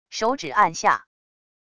手指按下wav音频